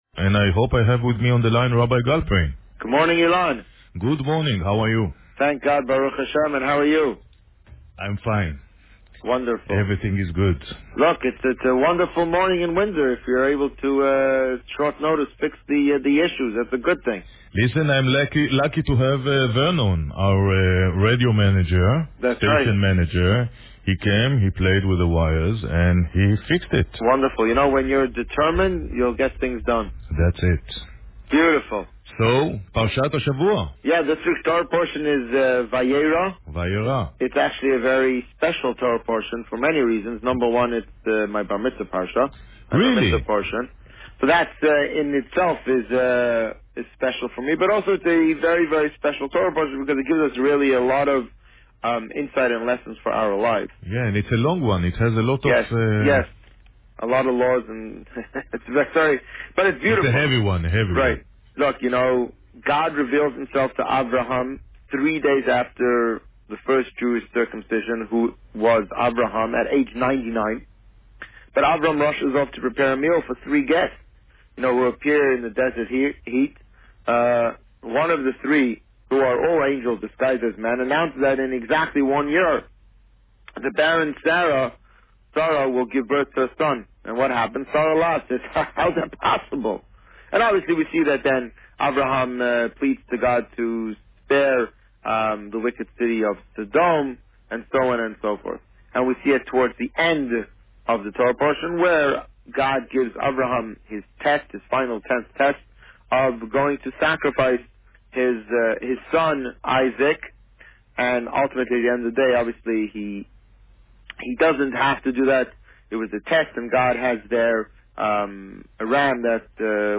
This week, the Rabbi spoke about Parsha Vayeira. Listen to the interview here.